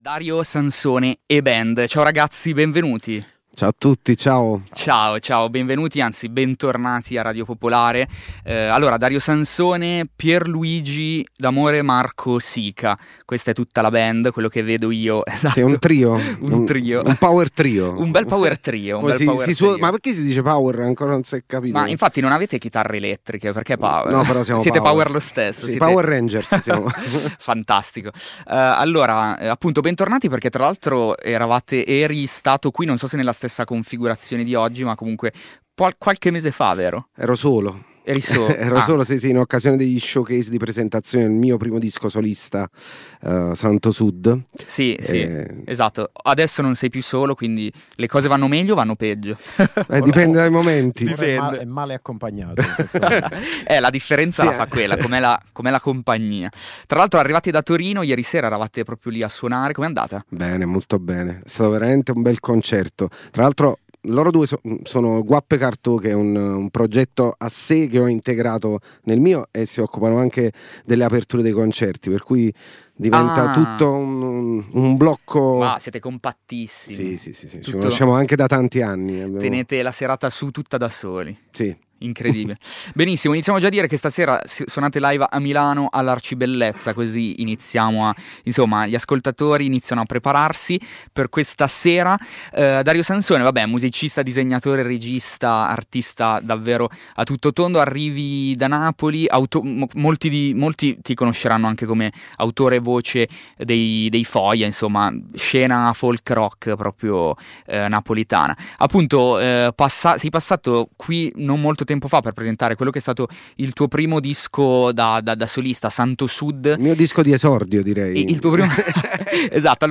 Ascolta l’intervista e il MiniLive